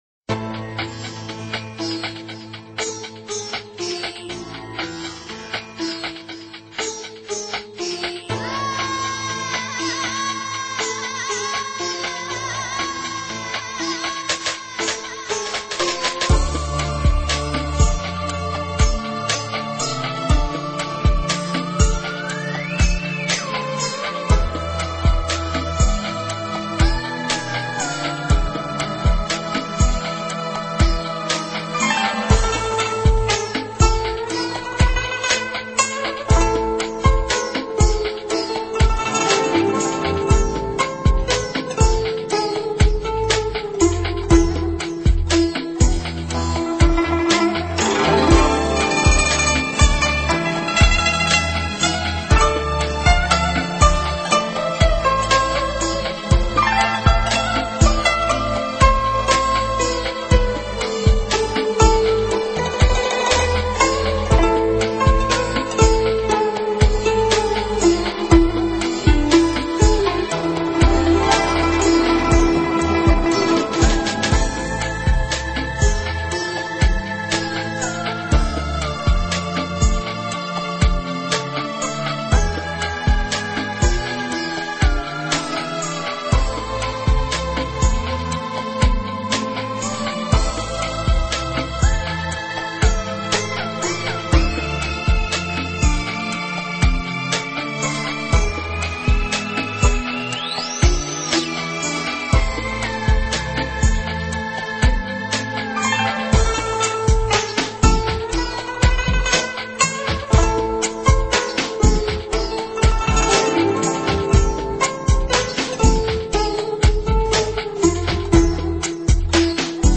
音乐类型：民乐  古筝
古筝以其独特的音质和气质，使其具有超凡的古文化内涵，清新脱俗、丝丝入情，给人一种别样的美。
那醉人的弦音带着一片脉脉的美在夕阳中挣扎、逼问、沉淀……